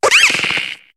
Cri de Zébibron dans Pokémon HOME.